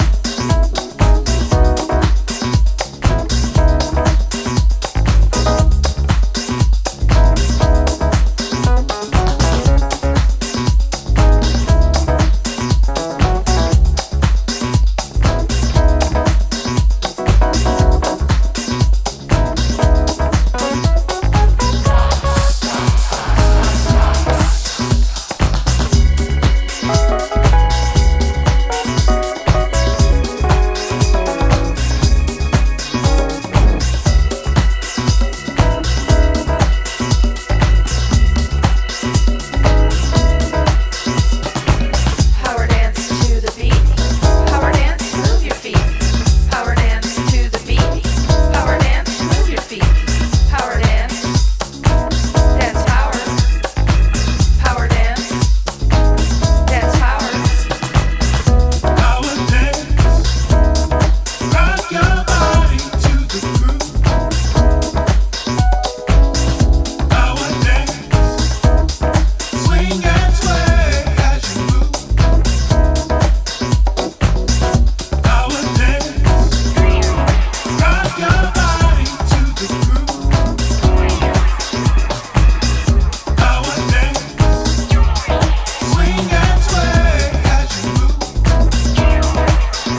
ジャンル(スタイル) NU DISCO / DEEP HOUSE